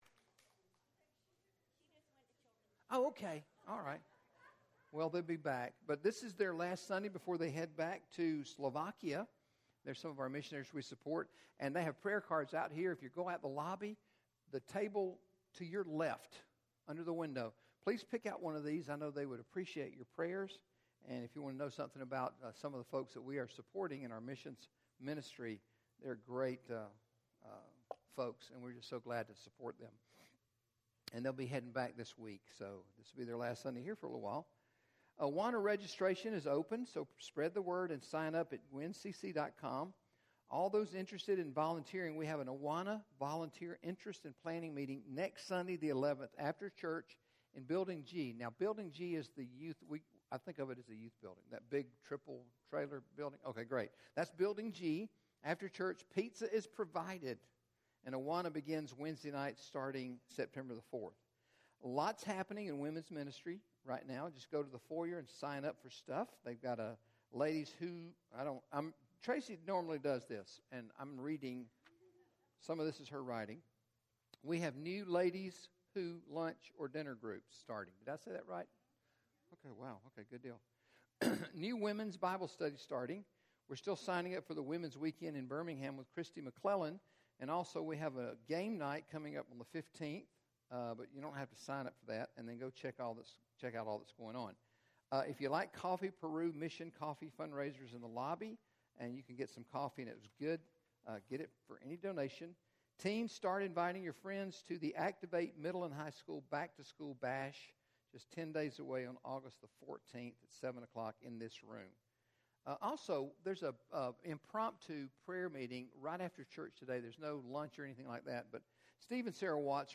GCC Sermons | Gwinnett Community Church Sermons